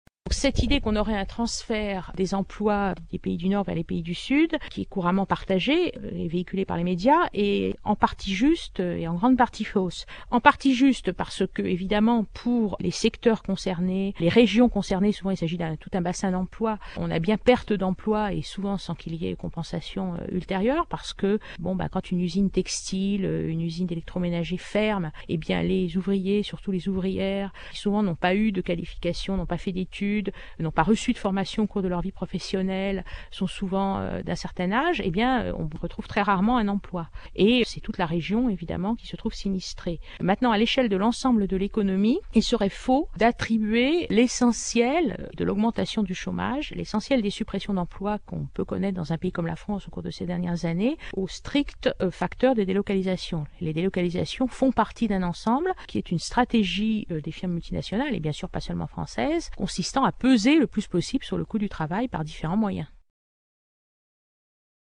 Cet interview a été réalisé à l’issue de la projection du documentaire de Marie France Collard, Ouvrières du monde, (Belgique, 2000. 52’).